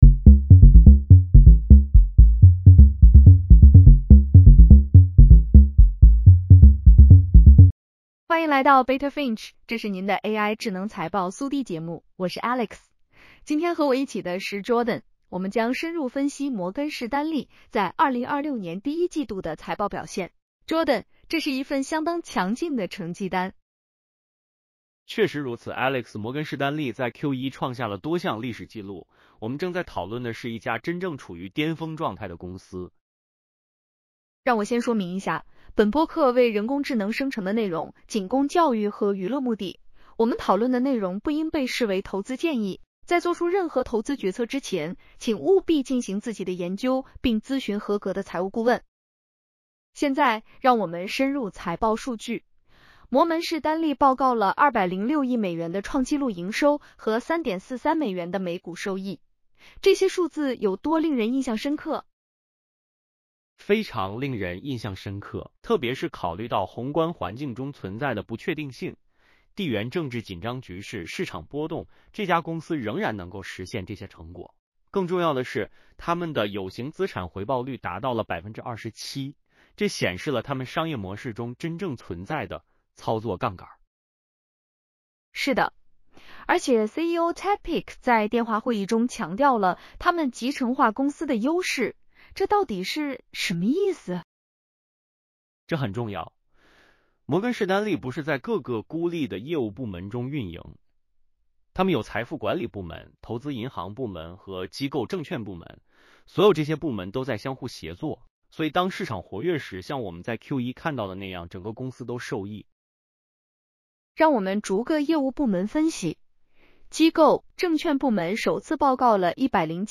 Morgan Stanley Q1 2026 earnings call breakdown.